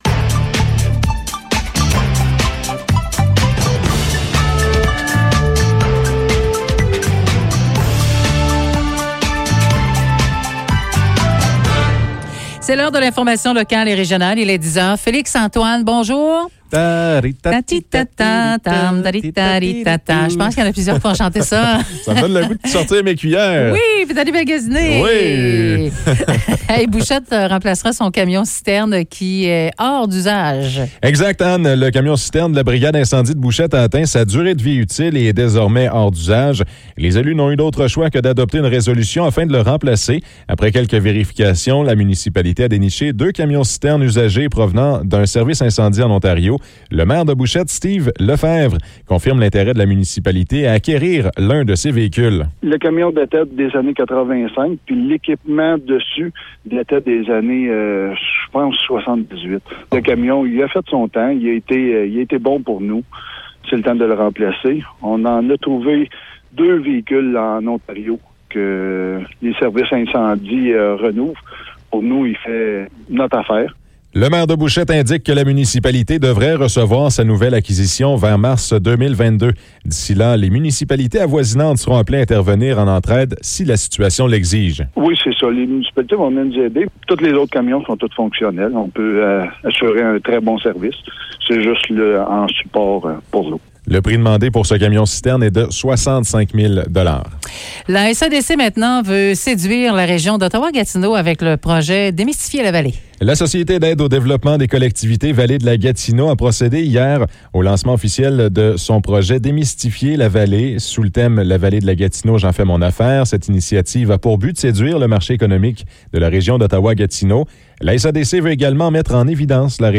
Nouvelles locales - 26 novembre 2021 - 10 h